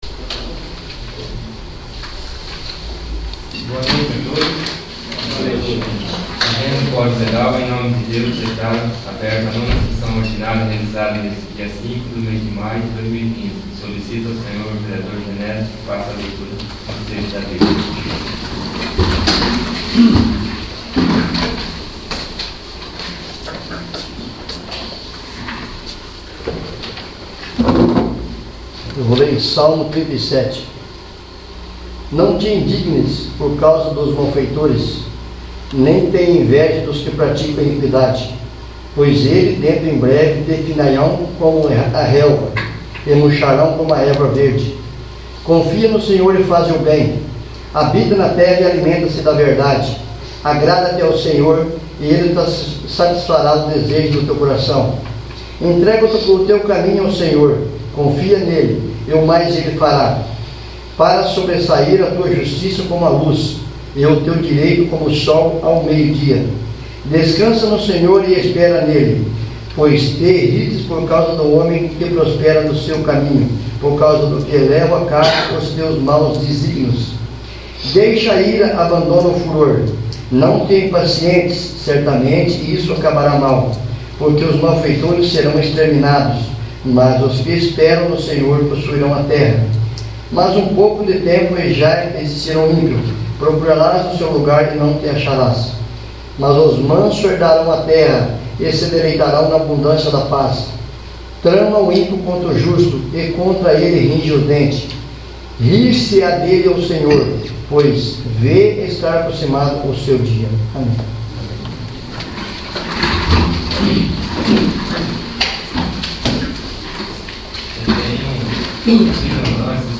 9º. Sessão Ordinária